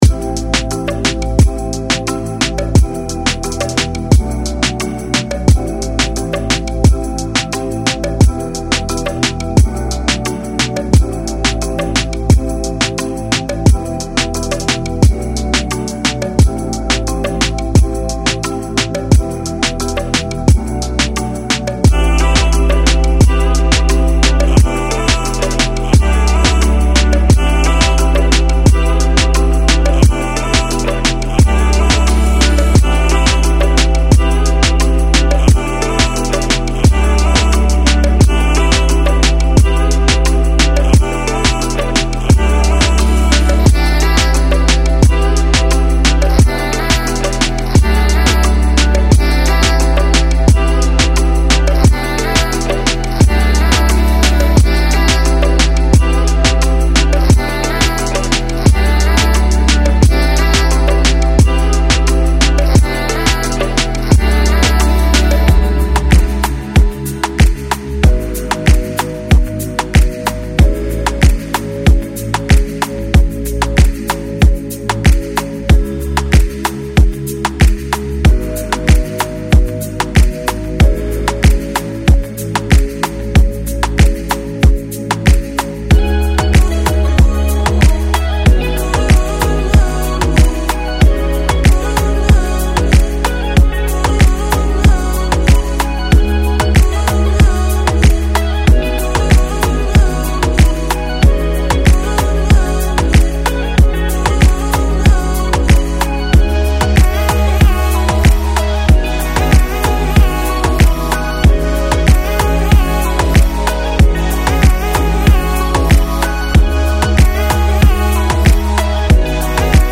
From rhythmic drum patterns and catchy basslines to melodic synth hooks and spicy percussion, each loop is expertly designed to inspire creativity and elevate your productions.
Authentic Reggaeton Vibes: Immerse yourself in the pulsating rhythms and tropical melodies that define the Reggaeton genre.
High-Quality Audio: All loops are professionally recorded and mastered to ensure optimal sound quality and seamless integration into your projects.
34 synth loops
27 drum loops
10 bass loops
8 vocal loops
13 percussion loops